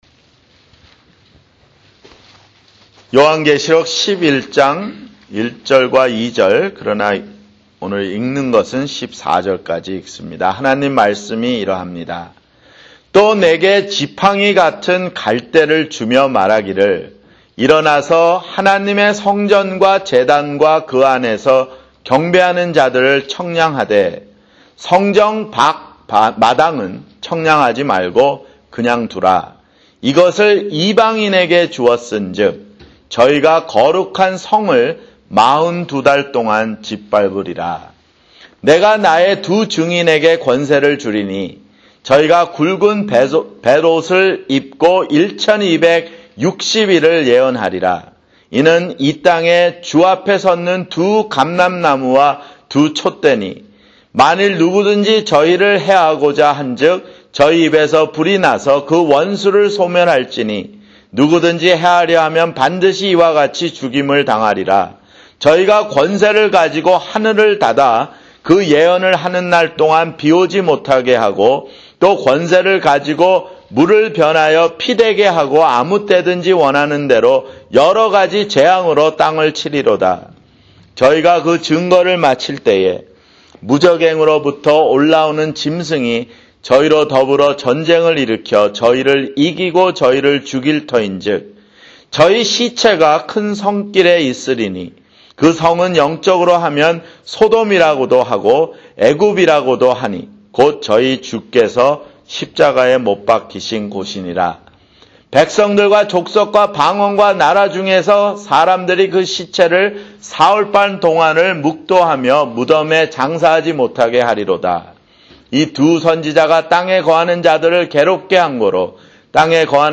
[주일설교] 요한계시록 (37)